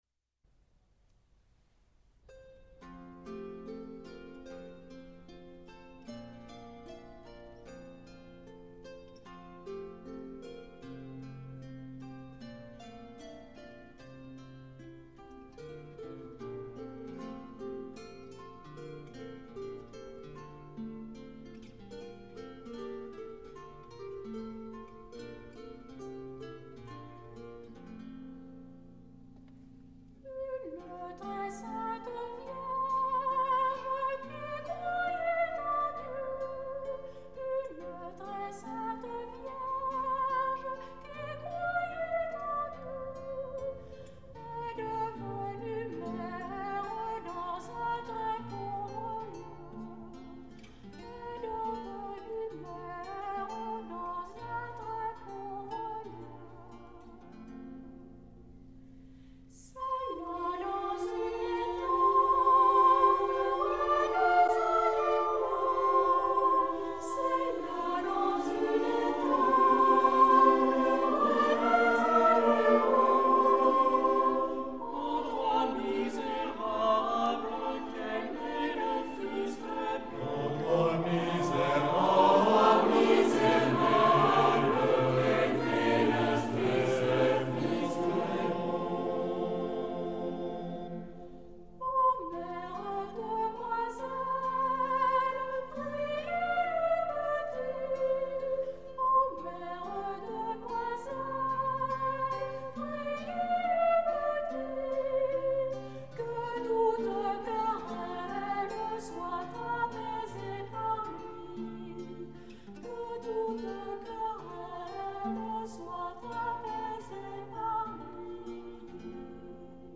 pour choeur a cappella SATB